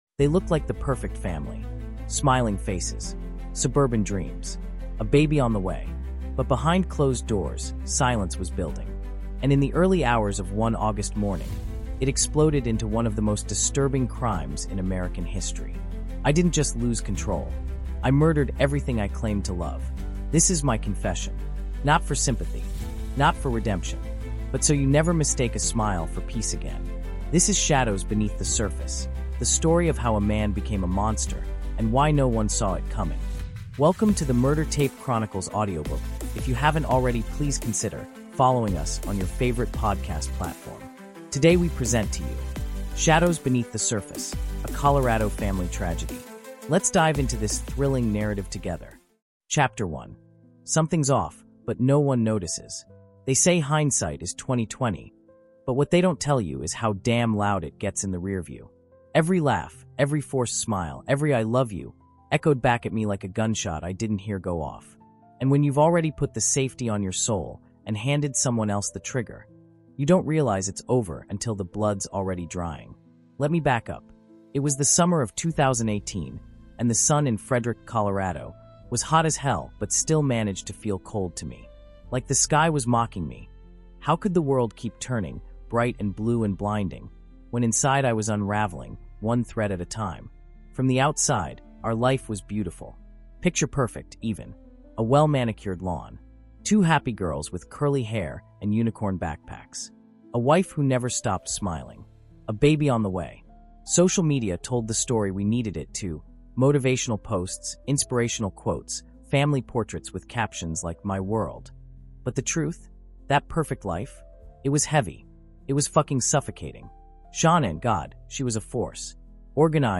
Shadows Beneath The Surface: A Colorado Family Tragedy | Audiobook
In August 2018, a suburban dad in Colorado murdered his pregnant wife and two young daughters, then staged a heartless cover-up that stunned the world. Now, for the first time, you’ll hear the full story in his voice—a raw, emotionally charged descent into betrayal, obsession, and the darkest corners of the human soul. Told with haunting realism and gut-wrenching detail, this is not just a crime story—it’s a psychological unraveling.